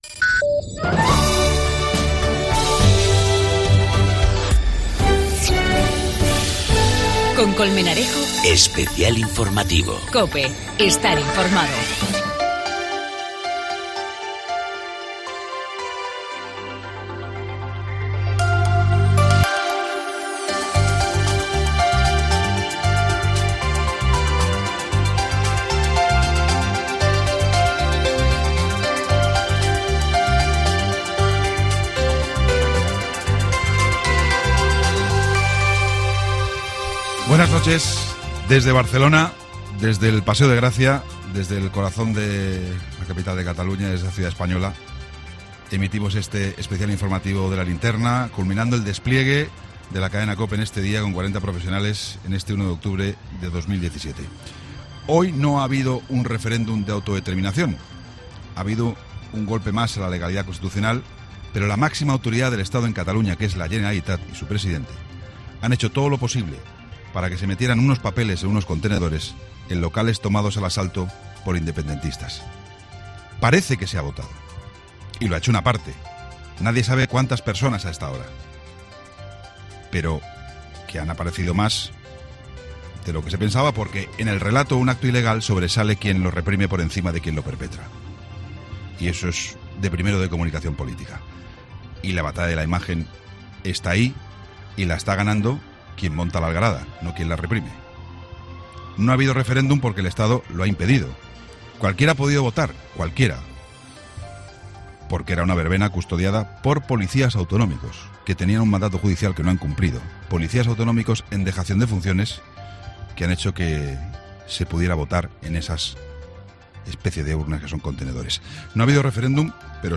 Informació des de les Rambles amb Plaça Catalunya. La actitud dels Mossos d'Esquadra, la Policia Nacional i la Guardia Civil.
Opinió d'una resident anglo escocesa i d'una asturiana.
Gènere radiofònic Informatiu